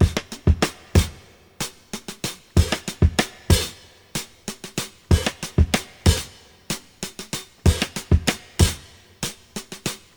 • 94 Bpm Fresh Drum Loop E Key.wav
Free drum groove - kick tuned to the E note. Loudest frequency: 1685Hz
94-bpm-fresh-drum-loop-e-key-JO6.wav